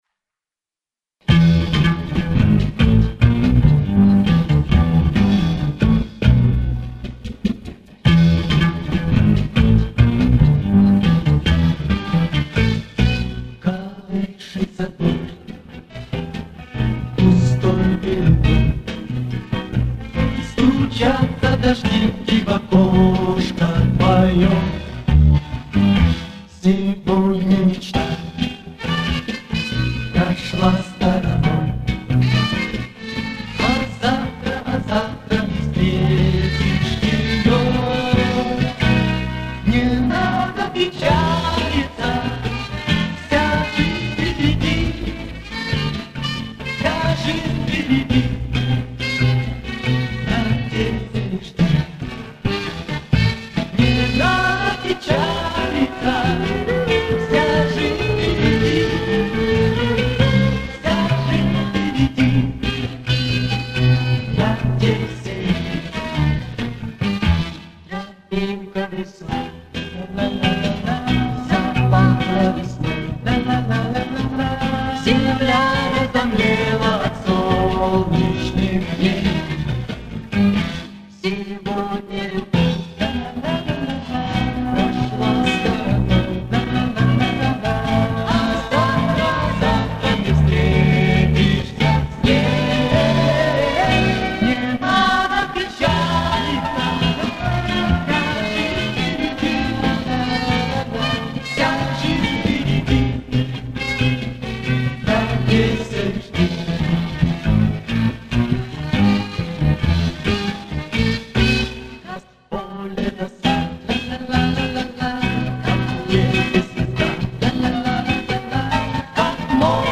Звук профессиональный.
Запись концертная.